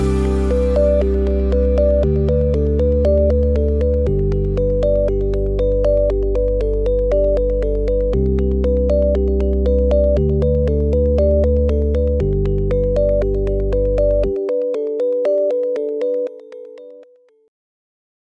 Красивая мелодия смс для вашего телефона